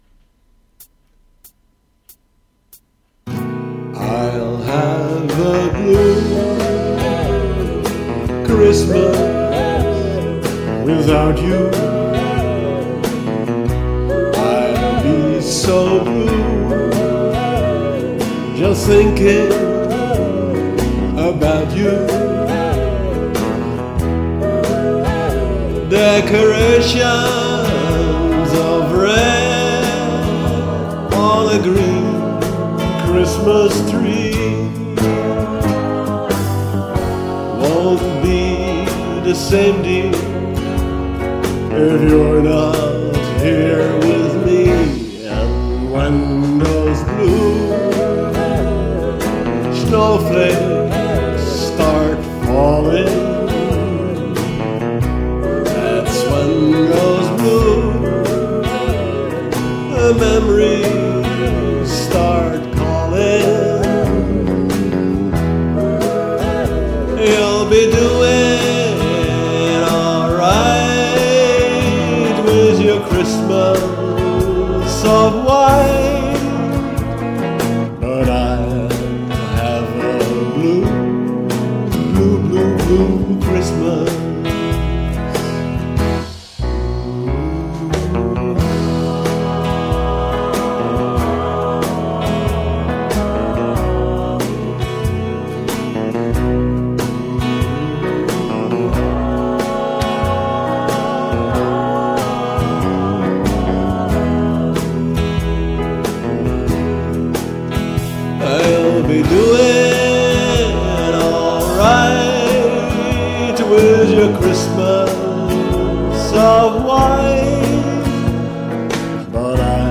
En tot een vrolijk kerstliedje 😉 .